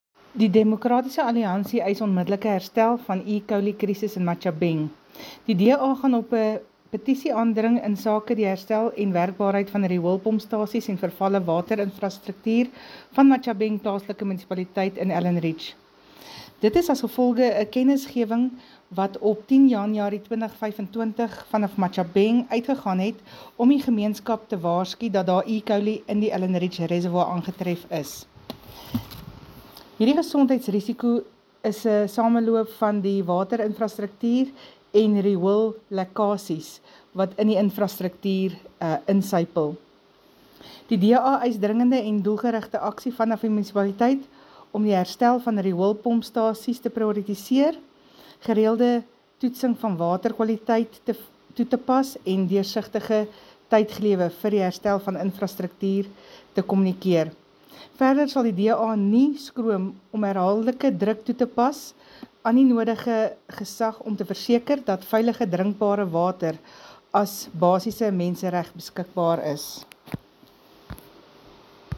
Afrikaans soundbites by Cllr Jessica Nel and